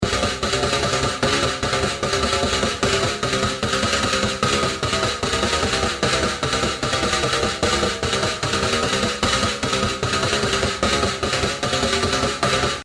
合成器舞蹈样本 " 137合成器舞蹈序列
描述：一个技术性的合成回路